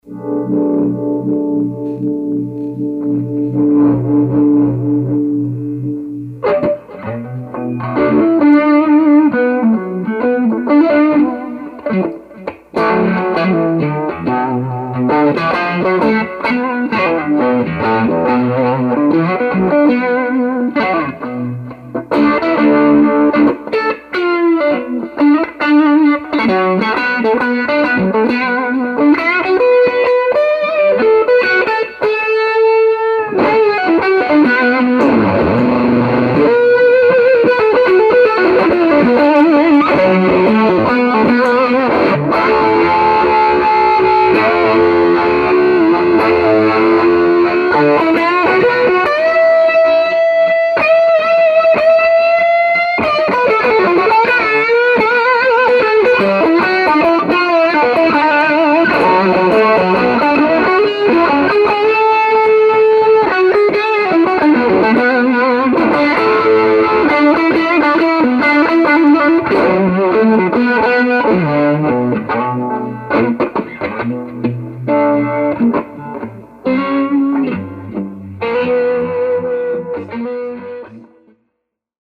There were recorded with a typical crappy PC mic directly into my PC.
Any crackly distortion is because of the mic, not what was going on in the real world. The volume is relatively low, & you can actually hear the clicking of the pedal switches, & pickup selector. Also, lots of buzz from the SC pickups being next to the PC monitor.
The first clip is the Neovibe going into a FD2 with the drive at 9 o'clock, then into a DL4 delay, & into a '70 Princeton Reverb with a 12" speaker, volume on 2. I kick in a Kama Sutra fuzz almost halfway into it.
No EQ, or anything, & the sound is a bit dark, but.....